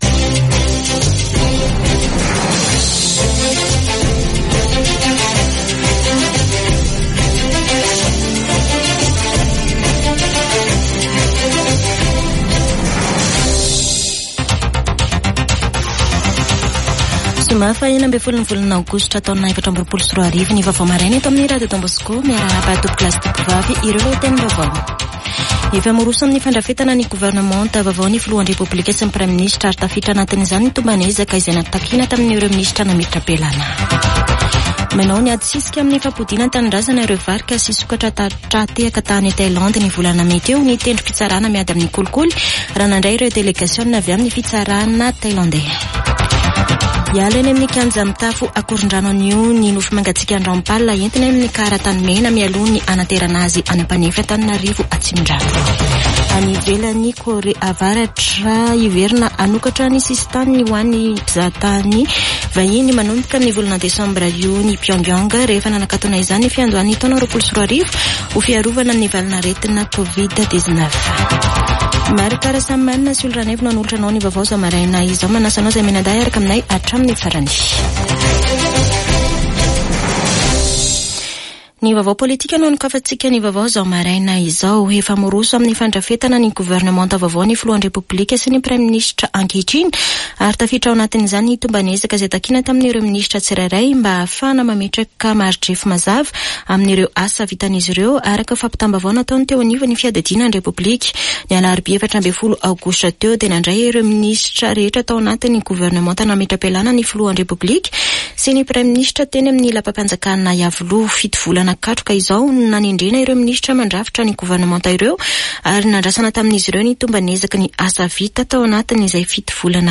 [Vaovao maraina] Zoma 16 aogositra 2024